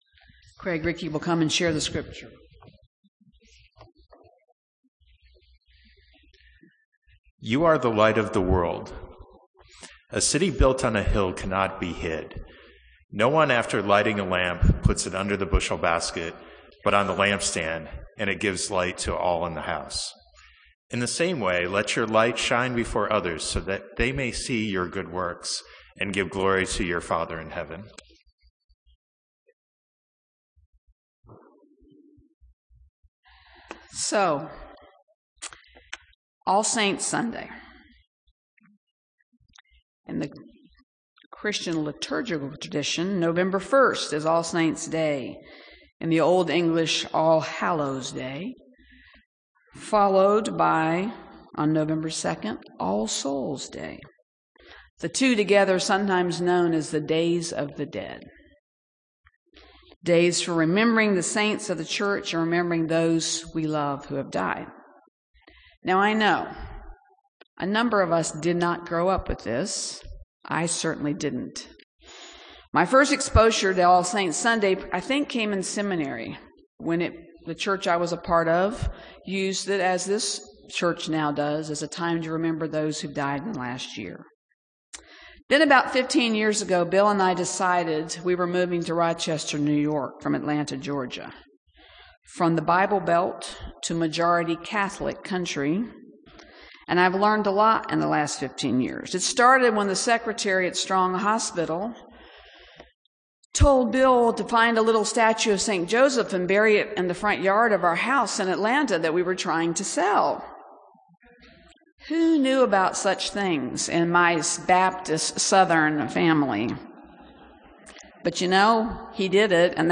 Recorded Sermons - The First Baptist Church In Ithaca